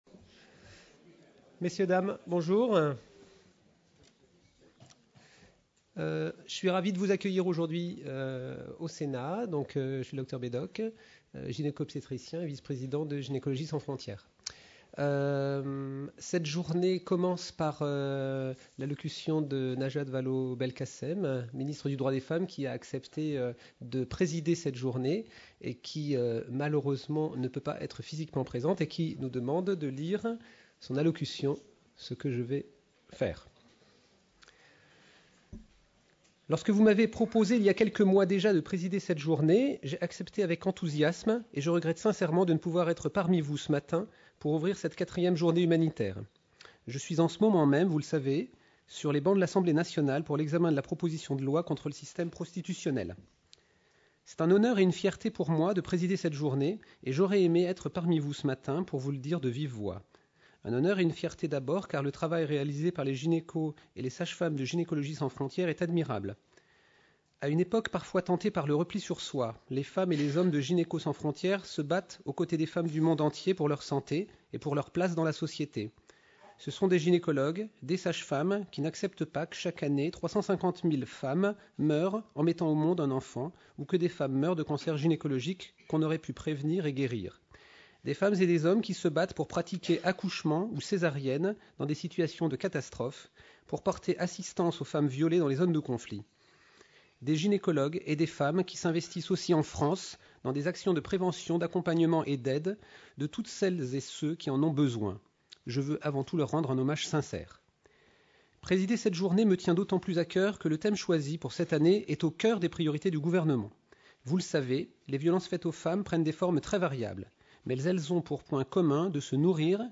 Journée organisée par Gynécologie Sans Frontières, le 29 novembre 2013, au Palais du Luxembourg (Paris).